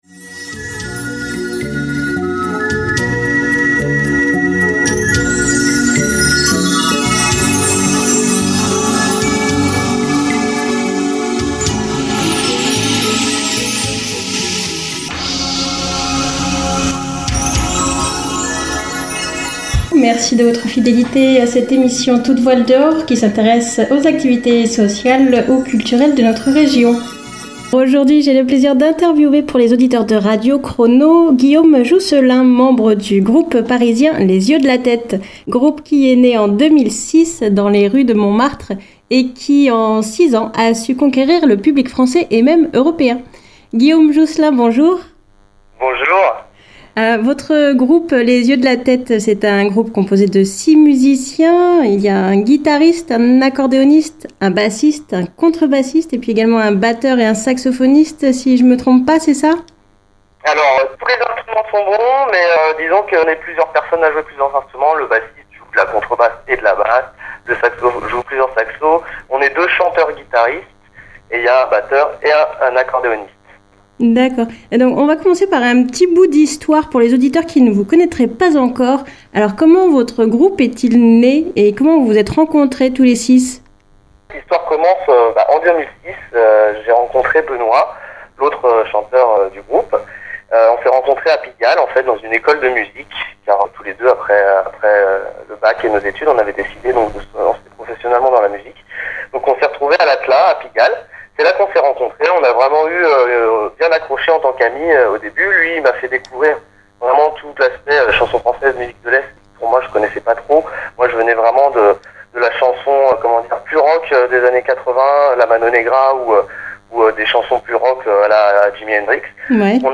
(phoner) Les…
Interview du groupe « Les Yeux d’la tête »
interview_les_yeux_dla_tete.mp3